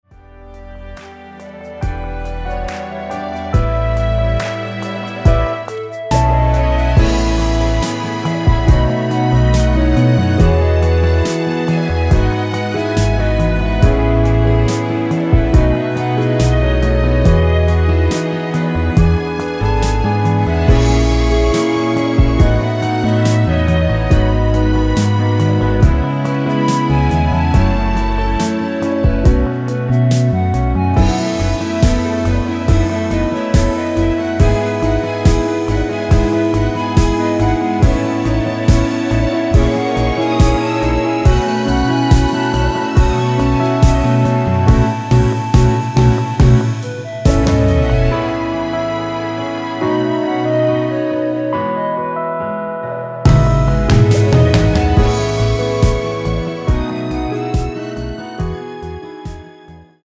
2절부분 삭제한 멜로디 MR 입니다.
음정과 박자 맞추기가 쉬워서 노래방 처럼 노래 부분에 가이드 멜로디가 포함된걸
앞부분30초, 뒷부분30초씩 편집해서 올려 드리고 있습니다.
중간에 음이 끈어지고 다시 나오는 이유는